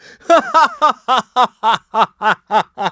Riheet-Vox_Happy4.wav